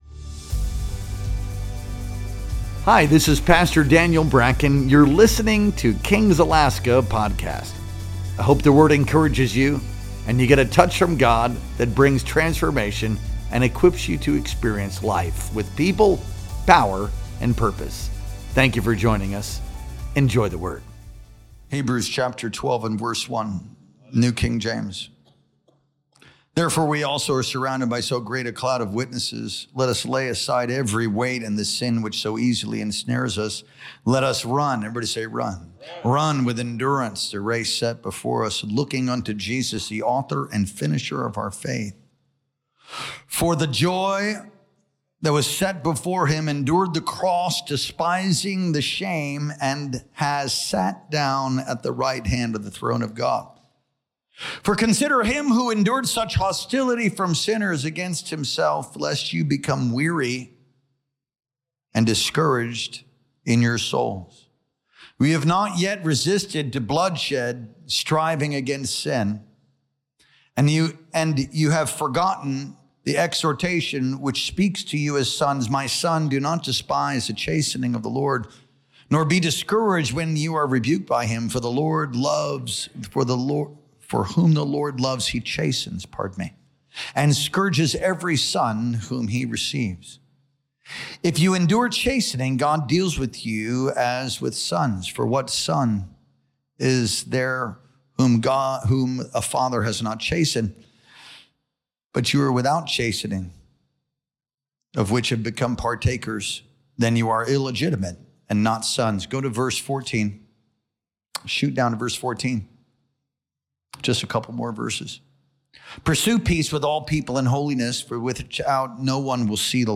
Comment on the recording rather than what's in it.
Our Wednesday Night Worship Experience streamed live on January 29th, 2025.